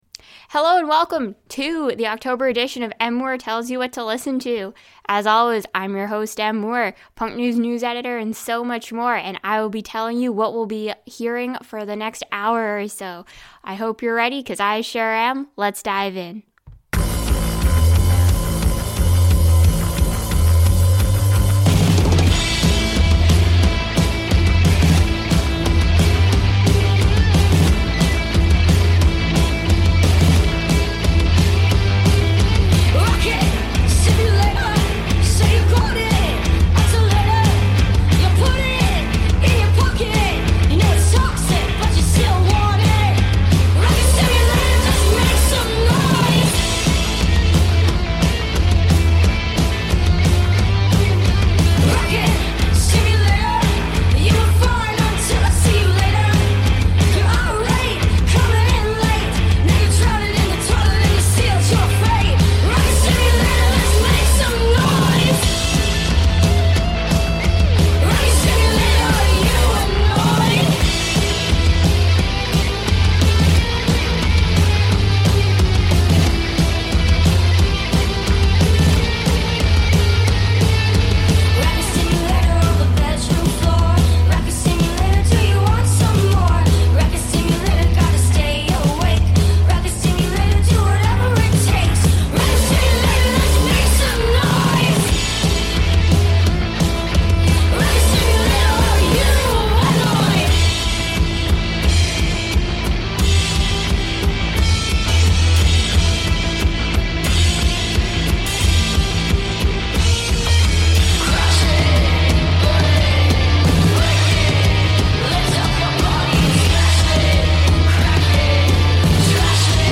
plays the hottest tracks from October!